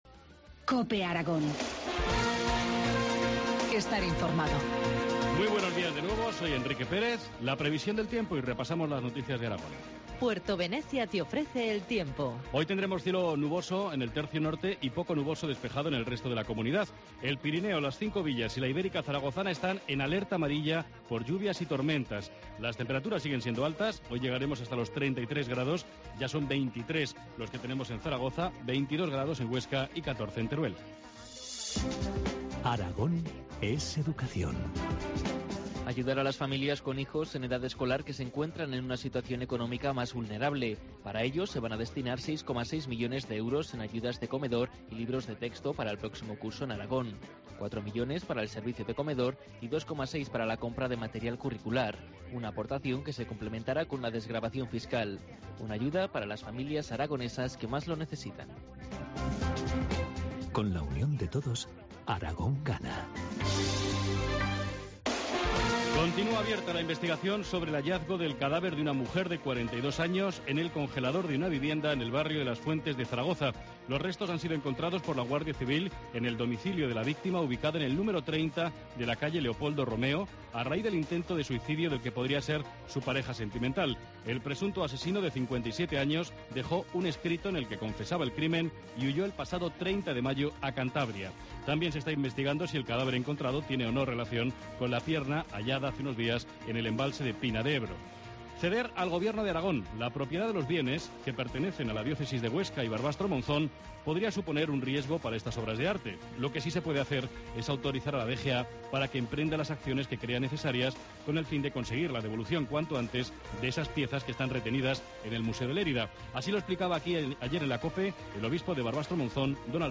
Informativo matinal, jueves 13 de junio, 8.25 horas